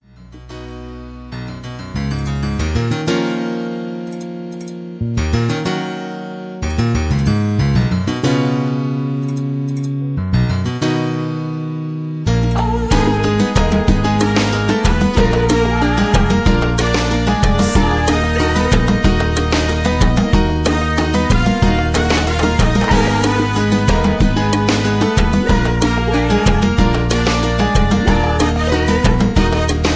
backing tracks , karaoke